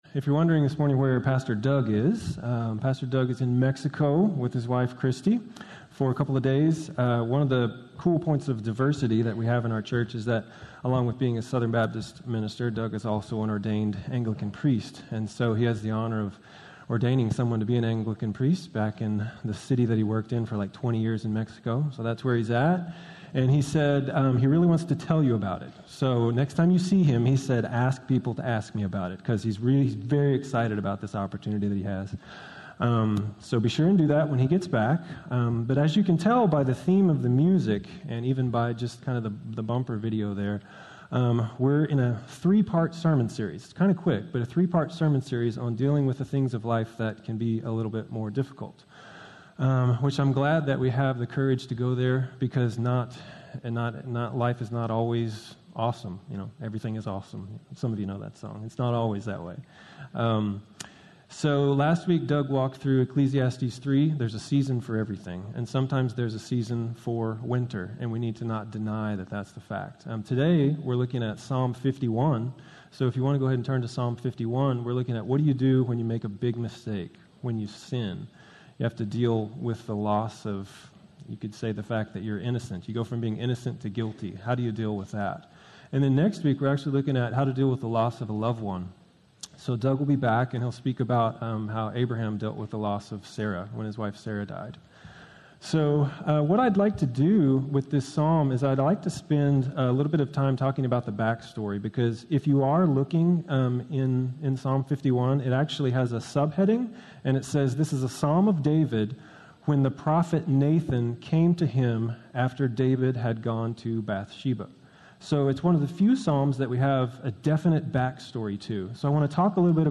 Letting Go of the Past - Sermon - Woodbine